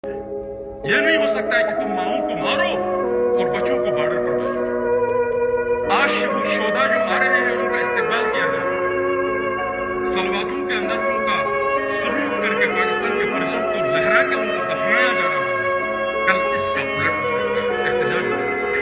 Nawaz Khan Naji Angry On Sound Effects Free Download